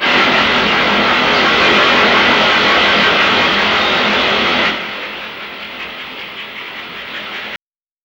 Si Fi.23.wav